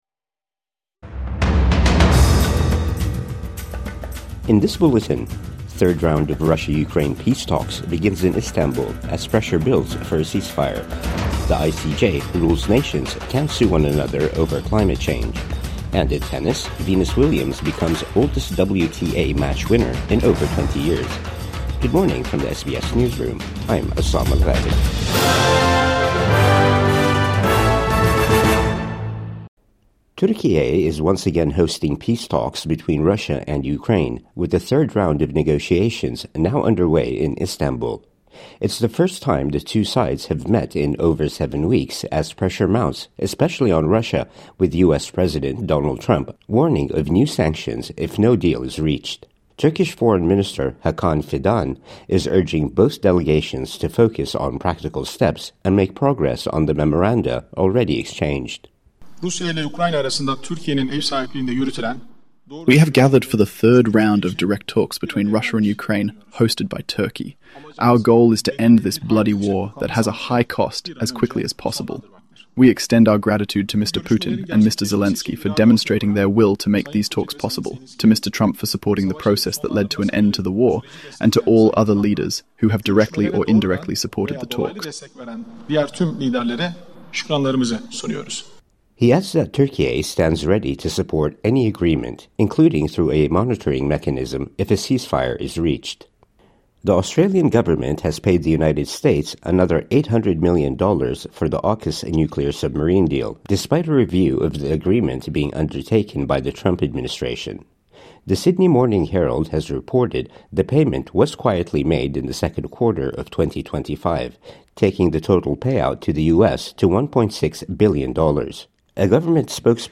Third round of Russia-Ukraine peace talks begins in Istanbul | Morning News Bulletin 24 July 2025